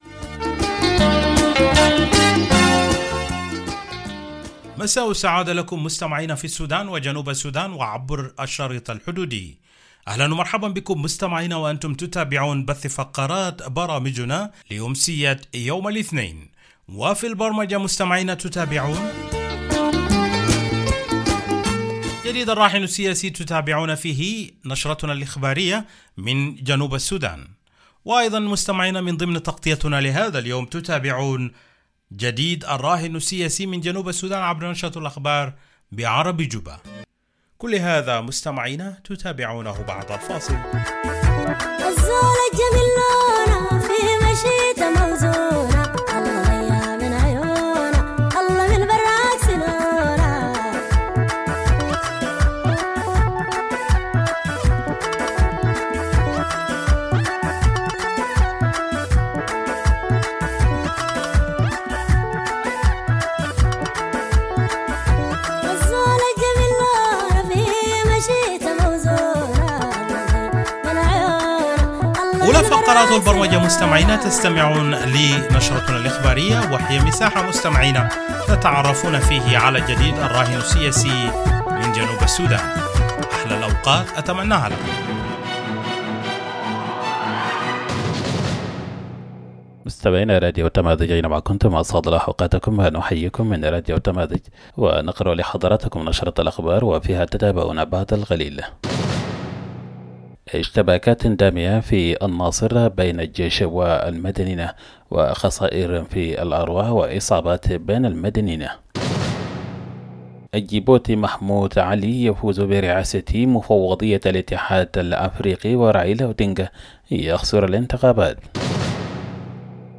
Evening Broadcast 17 February - Radio Tamazuj